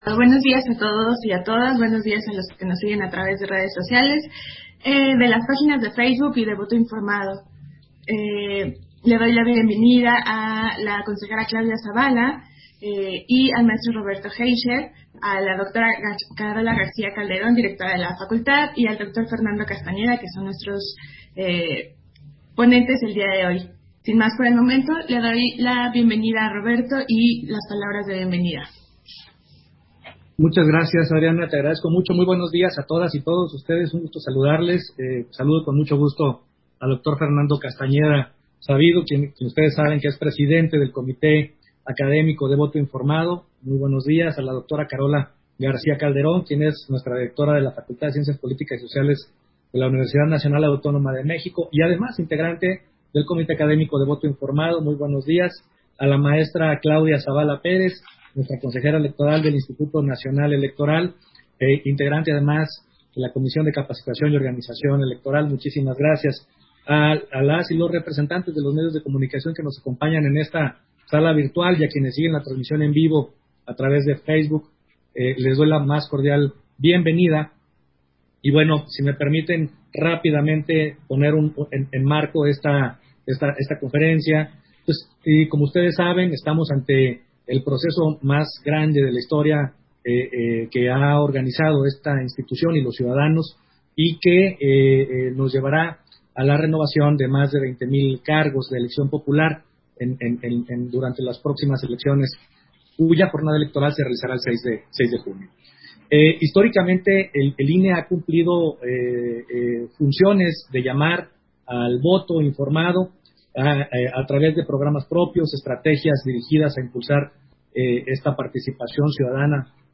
210421_AUDIO_CONFERENCIA-DE-PRENSA-PRESENTACIÓN-PROYECTO-VOTO-INFORMADO